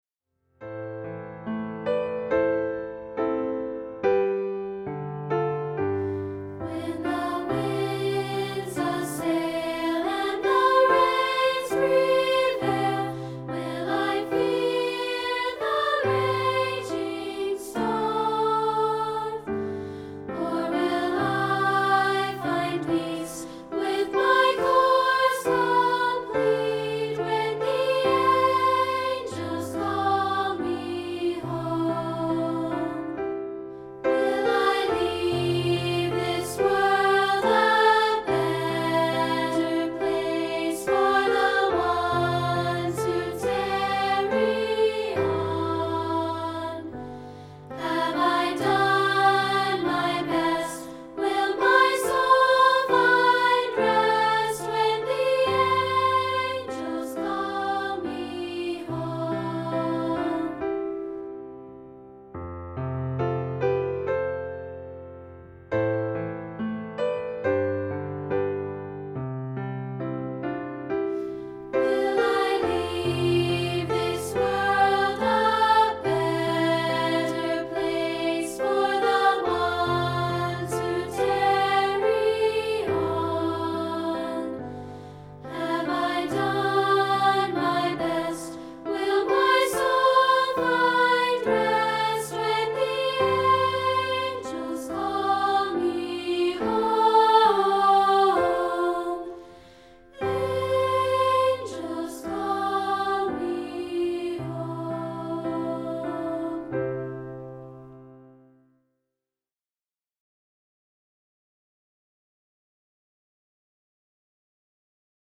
This is part 2, isolated.